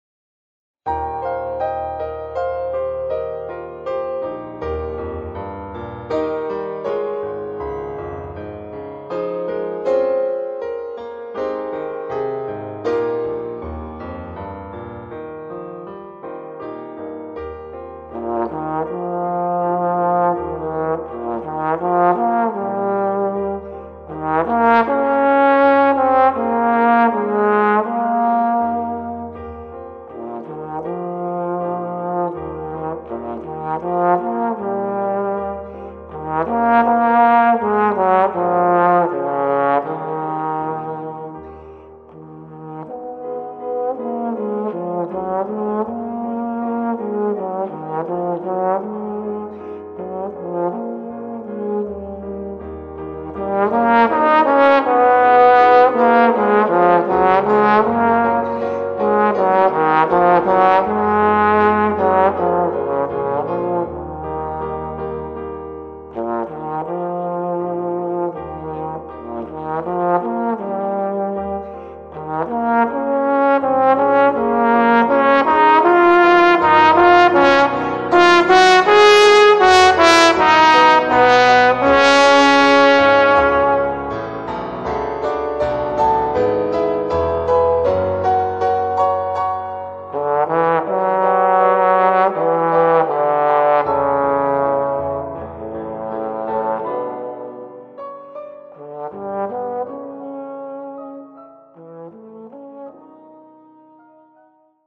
Voicing: Trombone and Piano